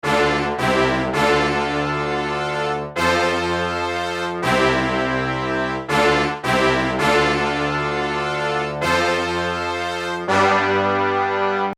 Record a simple 4 bar loop in your DAW using the horn ensemble you’ve chosen.
Here is the loop I’ve recorded.
Right now it sounds kind of thin so lets beef it up and layer it with 2 more horns that have slightly different tonal characteristics.
lush_horns1.mp3